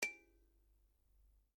tick.mp3